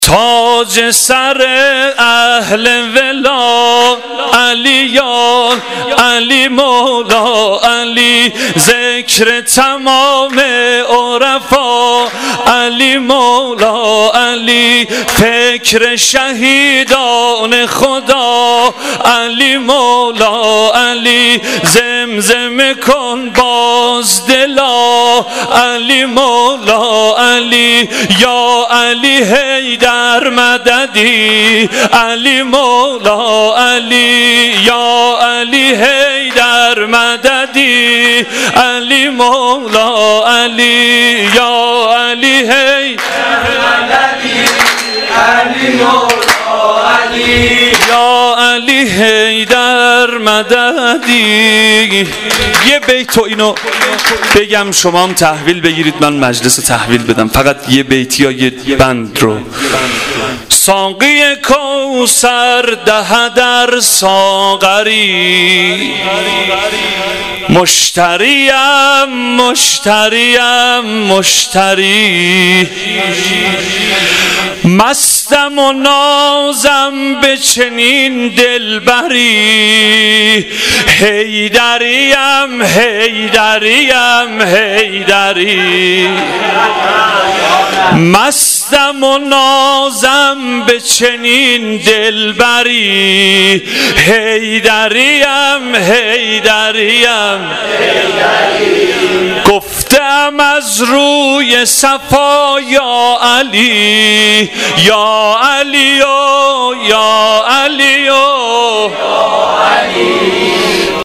سرود _ شب میلاد امام علی (علیه السلام)